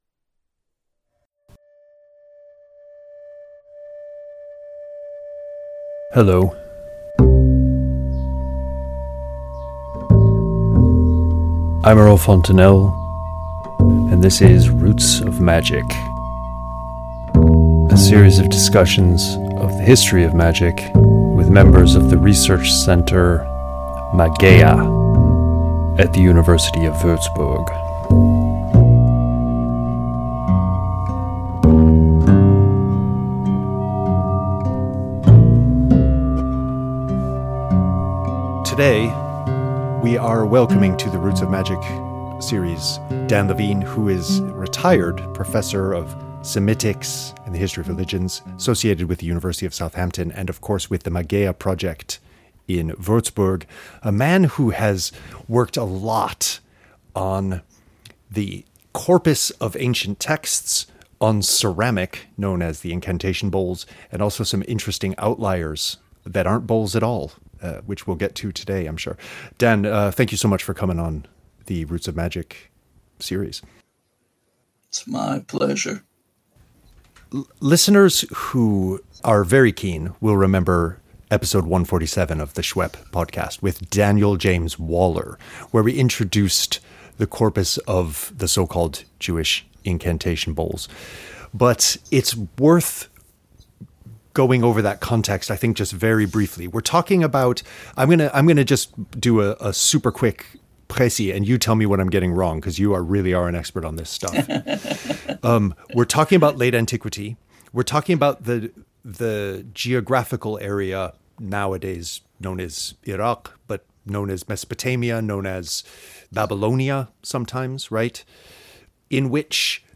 Roots of Magic Interview 5